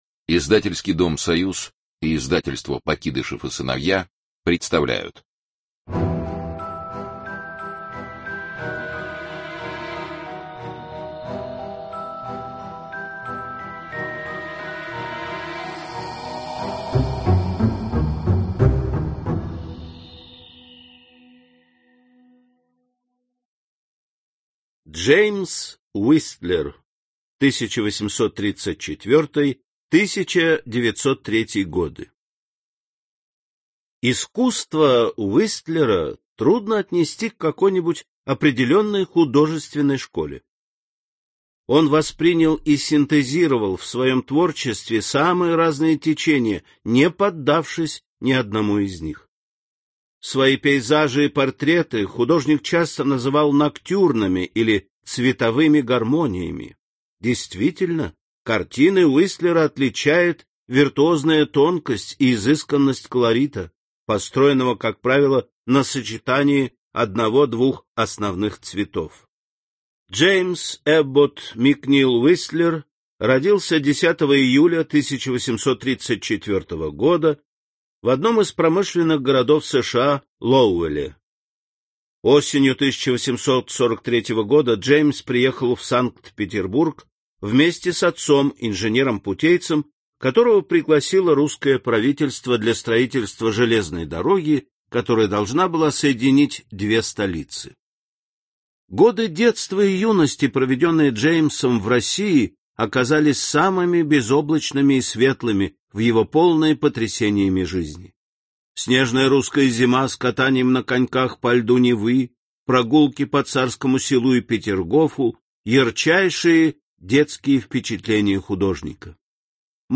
Аудиокнига Великие художники эпохи неоклассицизма и импрессионизма | Библиотека аудиокниг